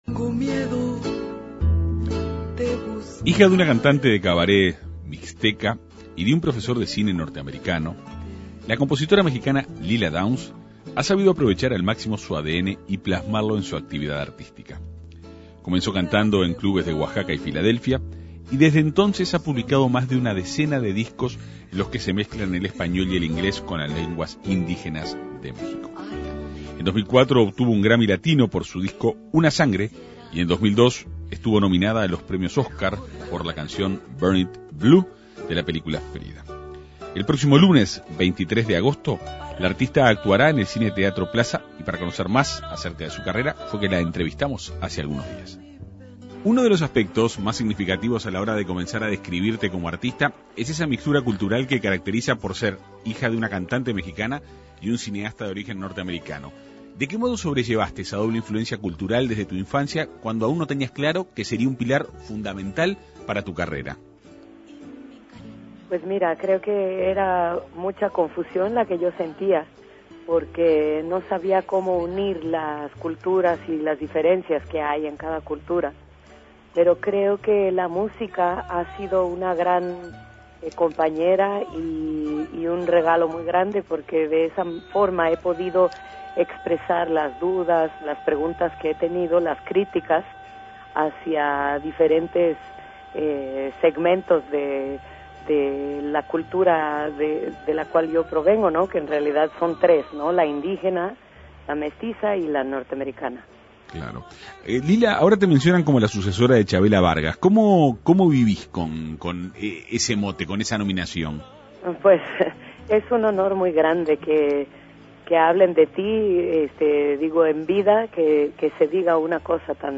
El próximo 23 de agosto, la artista actuará en el Cine Teatro Plaza. Para conocer más de cerca su carrera, fue entrevistada en la Segunda Mañana de En Perspectiva.